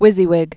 (wĭzē-wĭg)